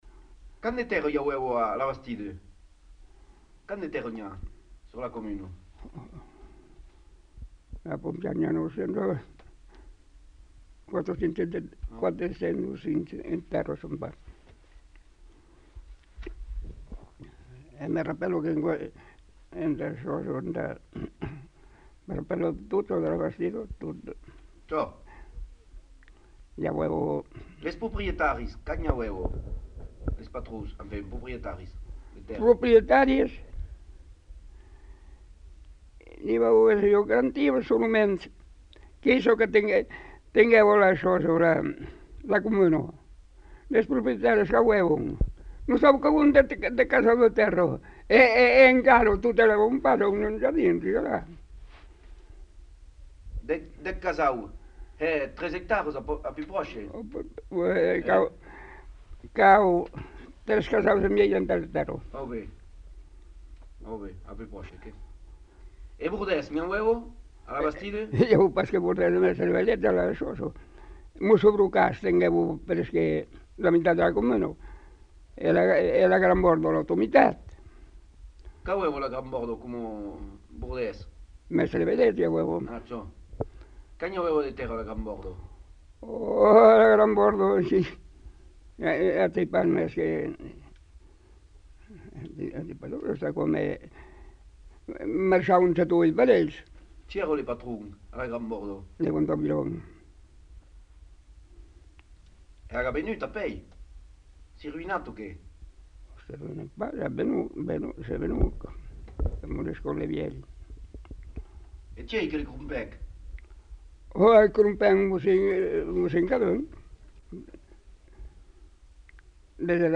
Lieu : Pompiac
Genre : témoignage thématique